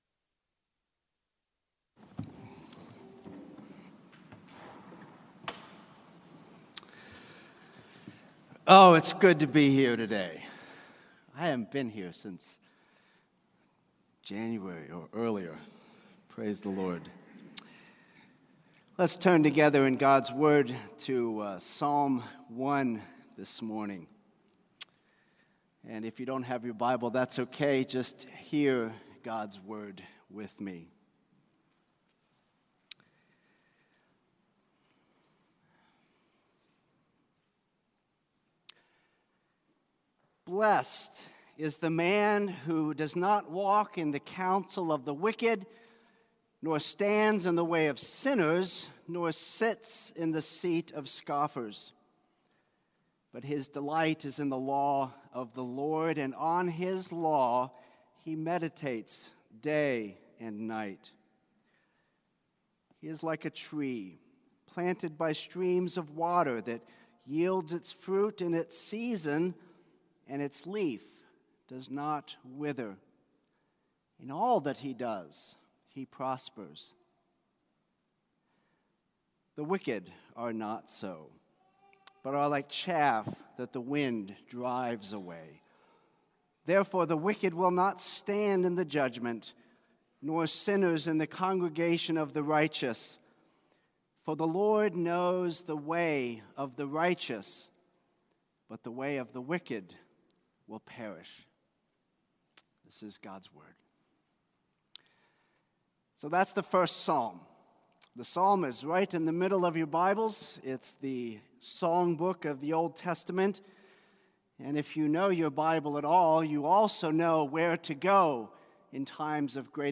Sermons on Psalm 1 — Audio Sermons — Brick Lane Community Church